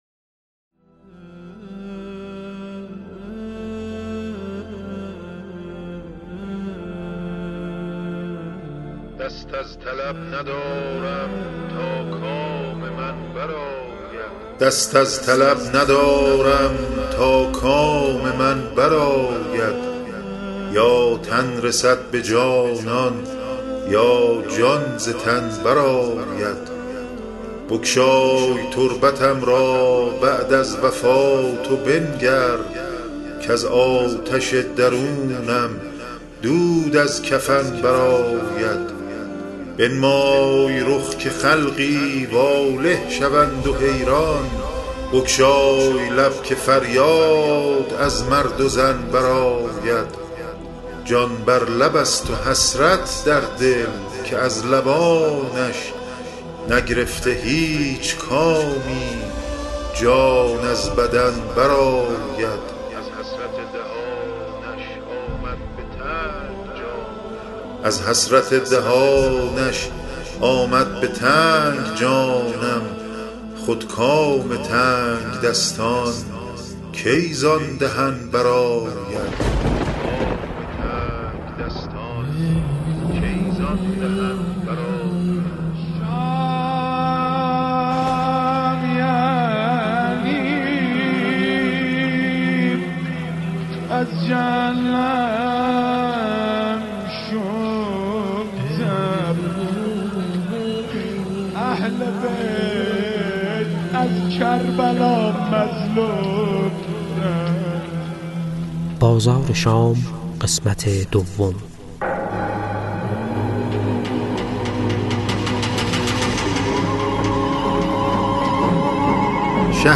روایت منزل به منزل کاروان اسرای کربلا (به صورت صباحا و مساء) به همت گروه التجا، مصائب کاروان اسرا، بر اساس منابع معتبر تاریخی در چهل قسمت به شکل فایل صوتی، آماده و به دوستداران امام عصر علیه‌السلام تقدیم شده است.